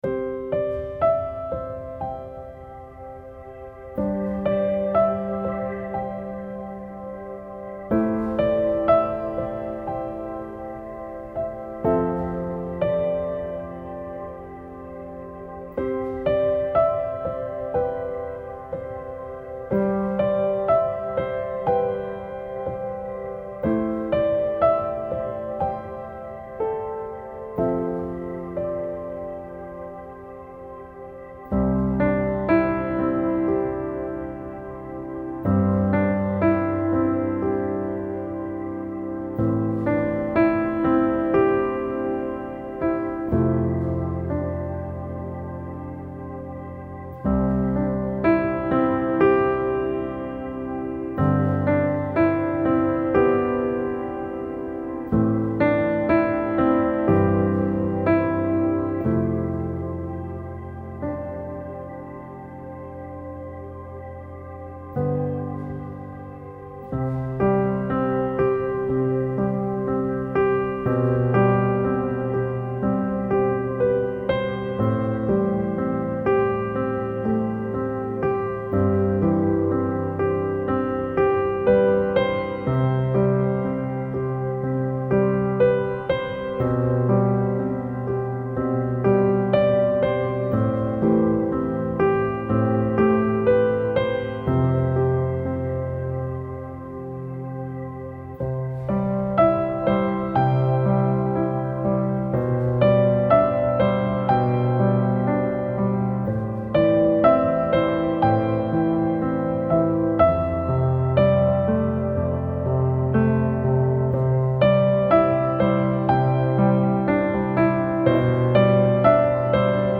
آرامش بخش
پیانو